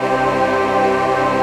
VOICEPAD22-LR.wav